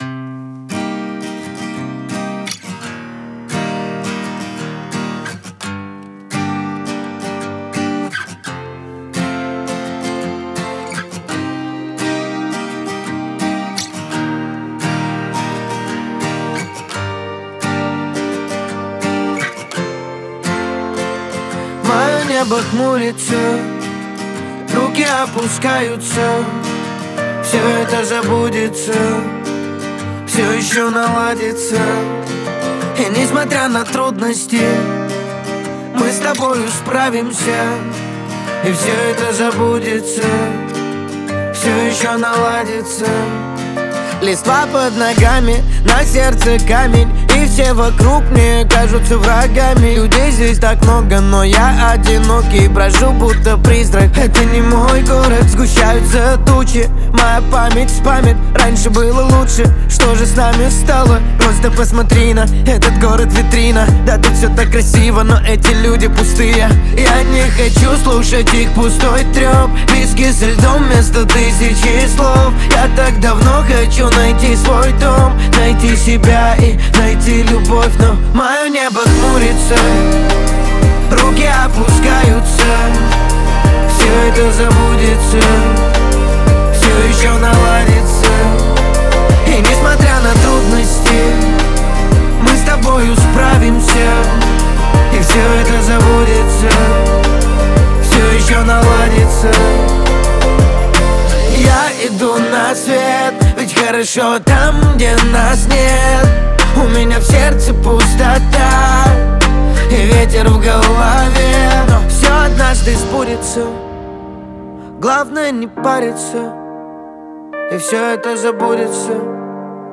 это энергичная песня в жанре хип-хоп